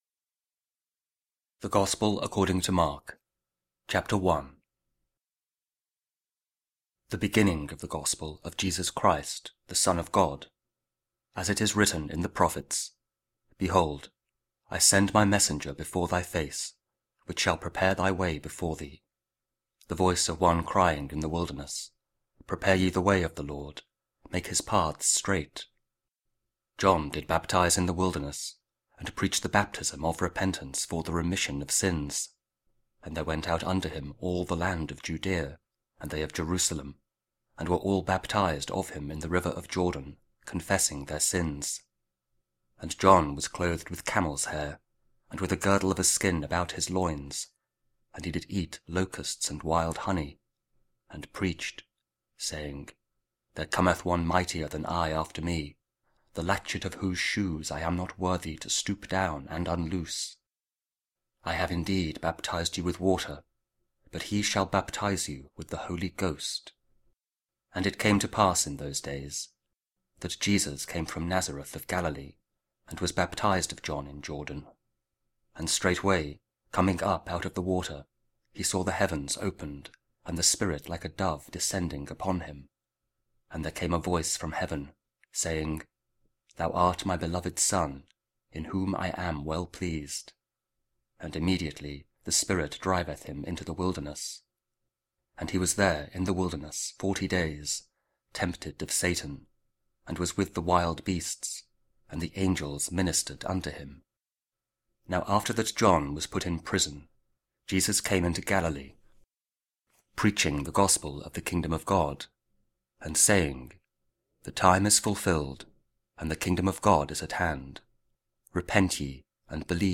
Mark 1: 21-28 – 4th Sunday Year B & Tuesday, Week 2 Ordinary Time (Audio Bible KJV, Spoken Word)